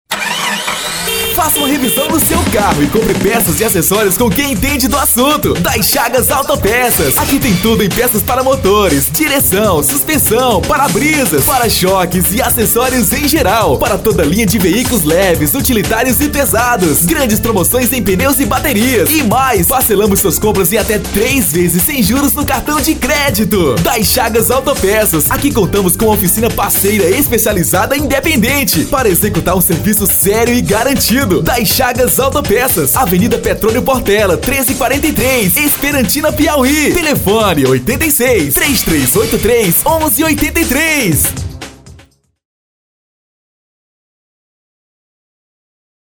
SPOT - DAS CHAGAS AUTO PEÇAS:
Estilo(s): Padrão
Animada